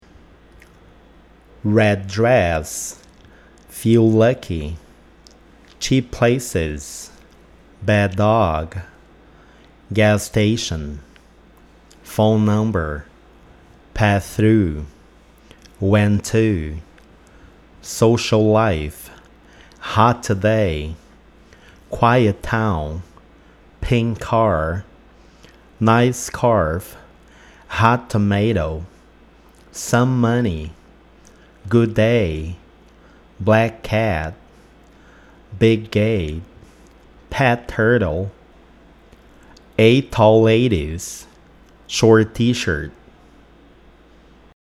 Se o mesmo som consonantal estiver no final de uma palavra e no começo de outra, também acontecerá a junção dos sons.